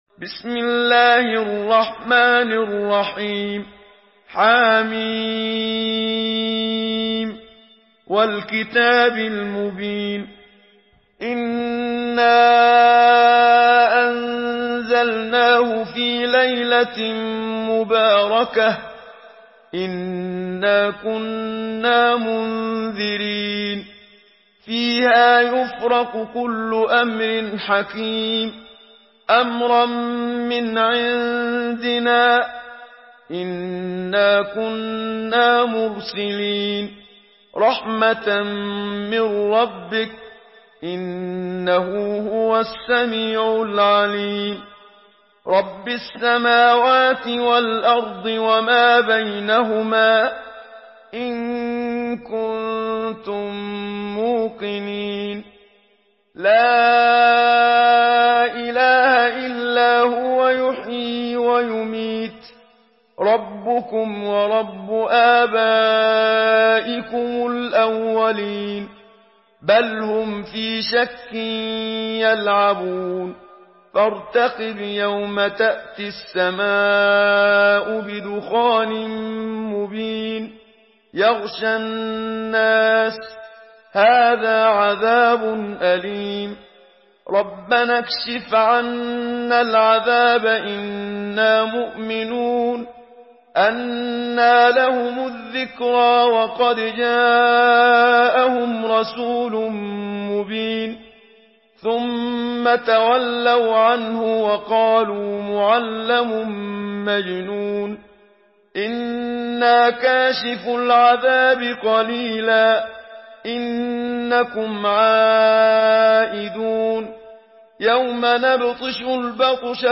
Surah আদ-দুখান MP3 in the Voice of Muhammad Siddiq Minshawi in Hafs Narration
Murattal Hafs An Asim